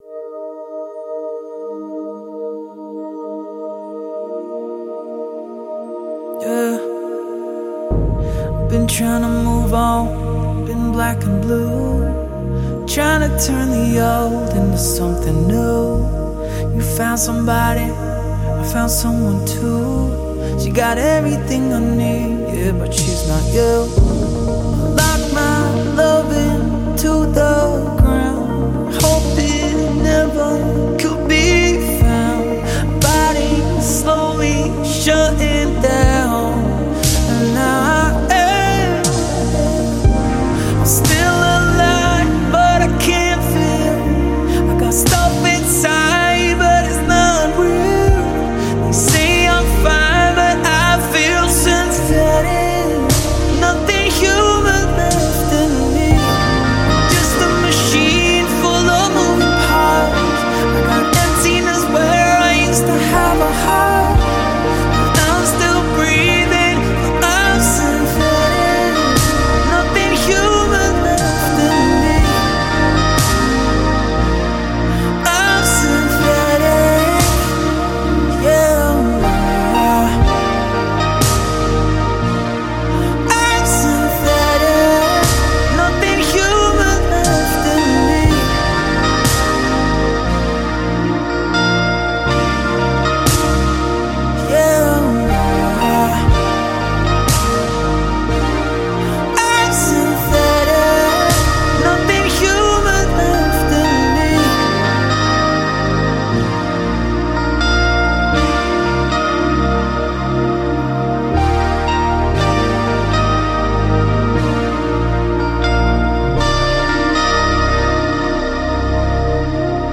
BPM60
MP3 QualityMusic Cut